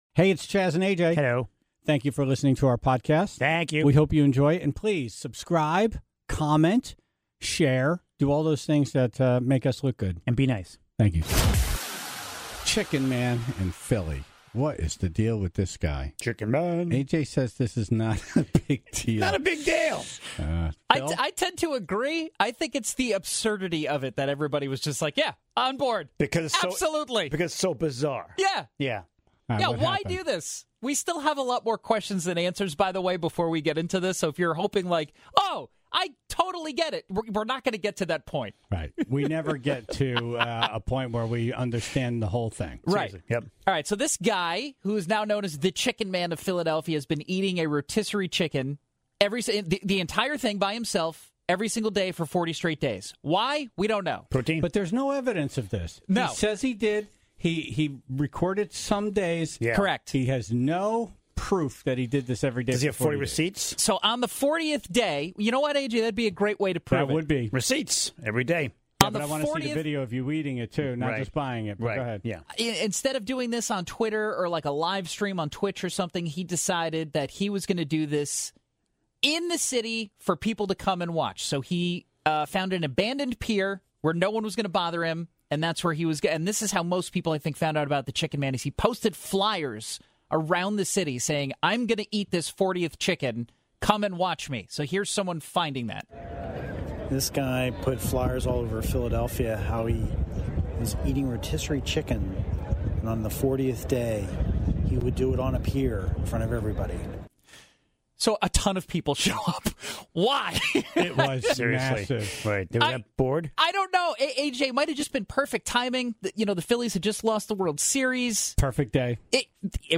He started eating a chicken around 8:07 AM, then the Tribe started to call in all they knew about grocery store chickens.